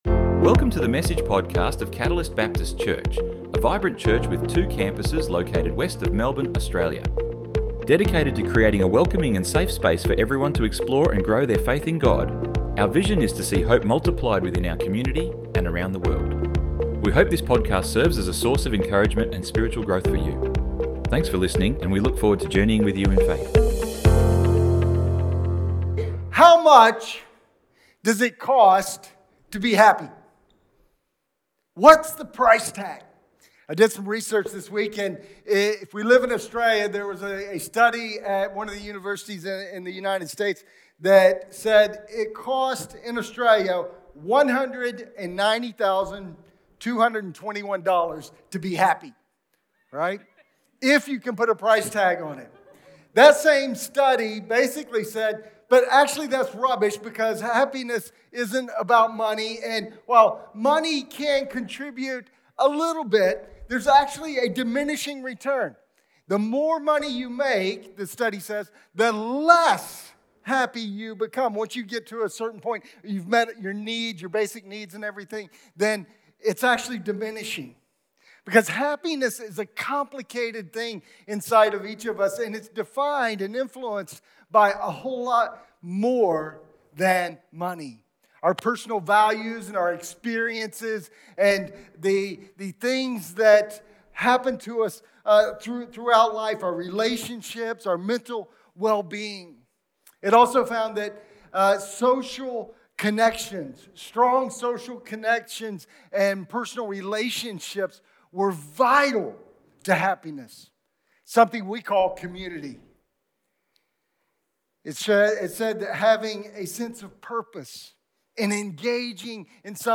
Download Download Sermon Notes 03-LG-notes-redefining-happiness-the-cost-of-happiness.pdf 03 - online notes - redefining happiness - the cost of happiness.doc We all want to be happy!